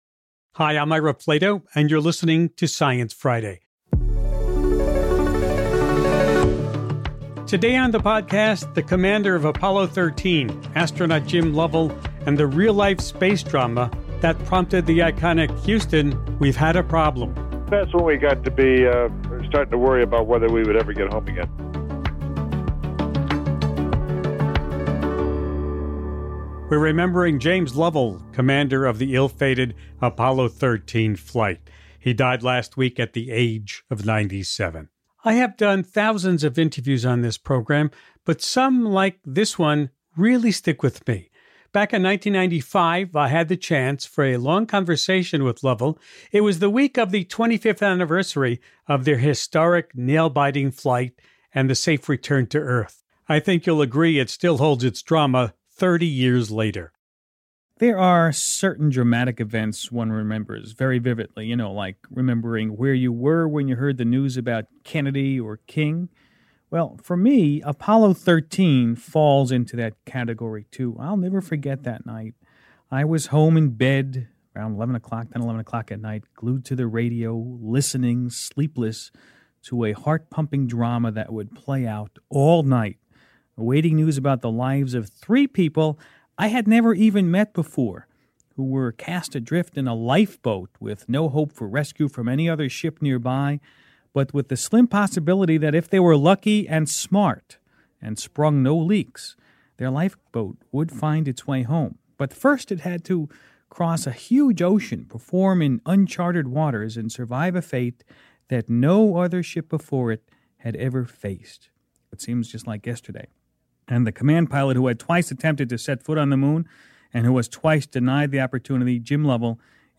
The late James Lovell was commander of the ill-fated Apollo 13 mission. In 1995, he recounted the story of the real-life space drama.
Guest: James Lovell was a NASA astronaut and commander of the Apollo 13 mission.